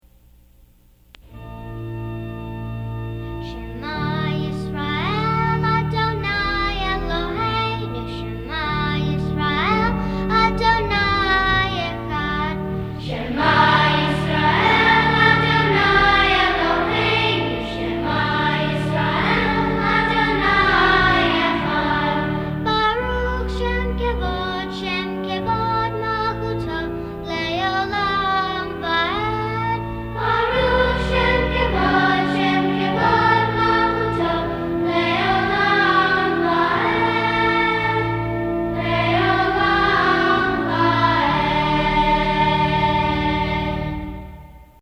“Sh’ma” from Junior Choir Songs for the High Holy Days.